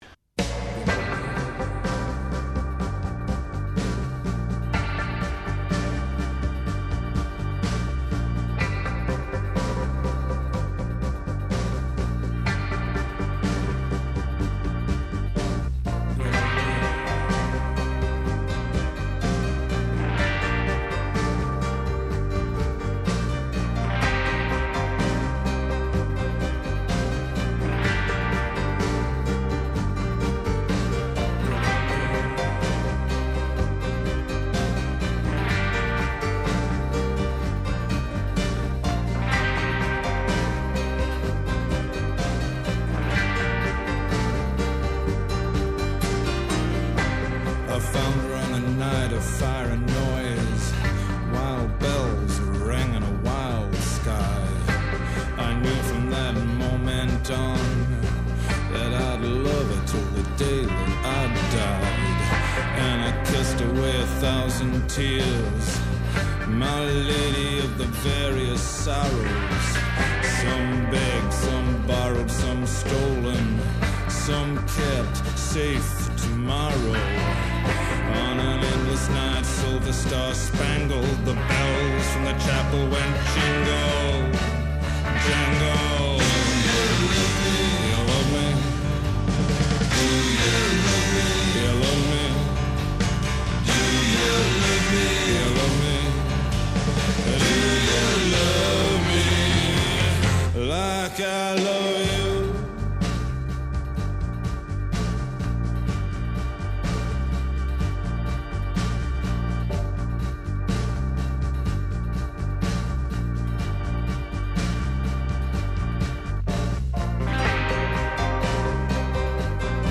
Alternative Rock, Gothic Rock